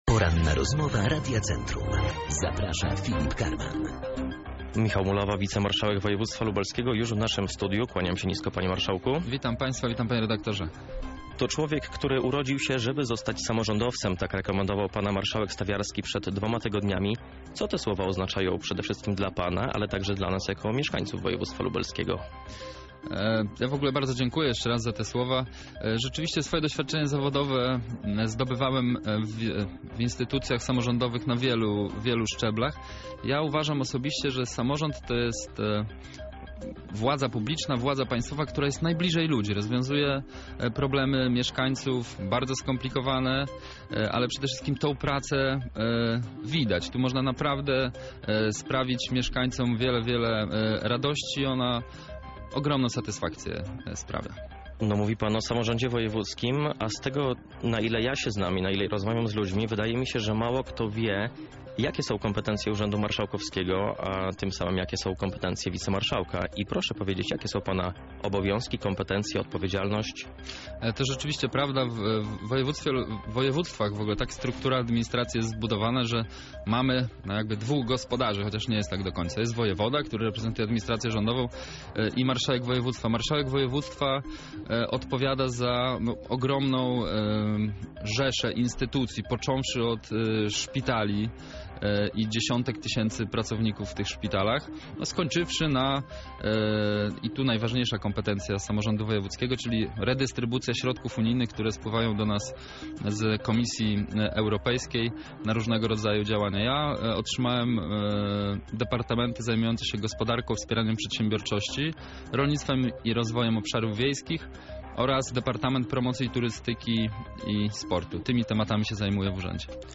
Całą rozmowę z Michałem Mulawą znajdziecie poniżej:
Poranna-Rozmowa-Radia-Centrum-M.-Mulawa-cz.-1.mp3